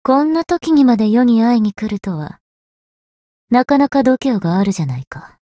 灵魂潮汐-萨缇娅-问候-雨天深夜-初识.ogg